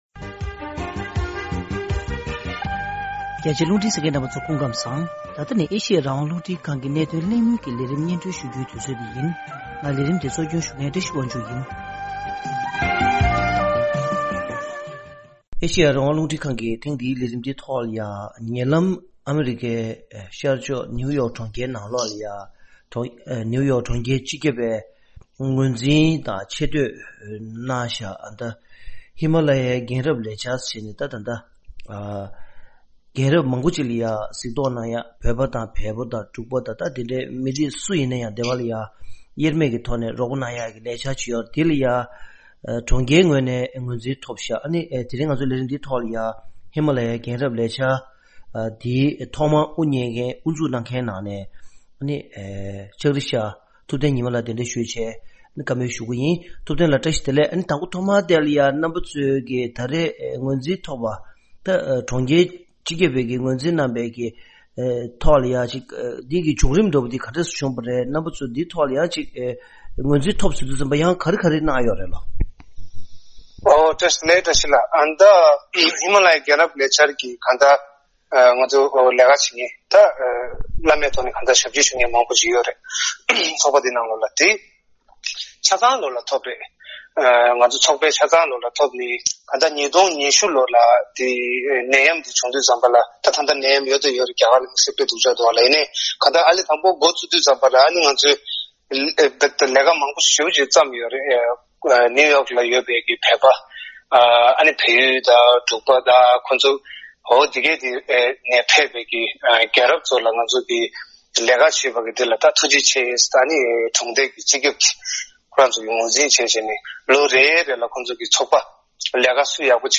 ཐེངས་འདིའི་གནད་དོན་གླེང་མོལ་གྱི་ལས་རིམ